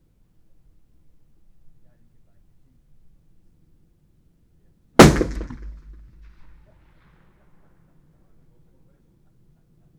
Environmental
Streetsounds
Noisepollution
UrbanSoundsNew / 01_gunshot /shot556_141_ch01_180718_163938_63_.wav